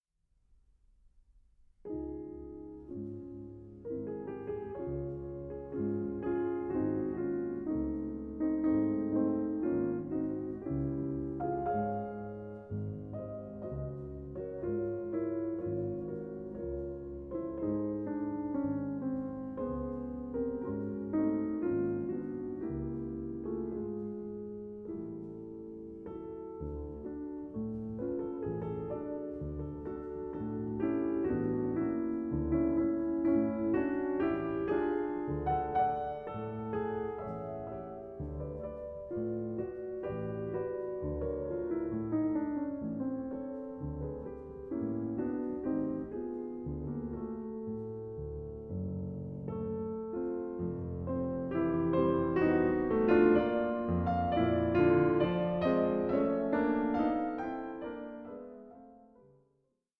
PIANISTIC PLEA FOR THE POWER OF MUSIC